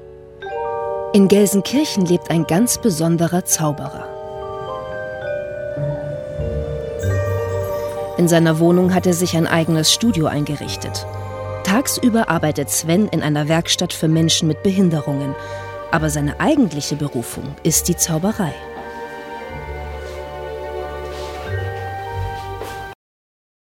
markant, sehr variabel, hell, fein, zart
Jung (18-30)
Doku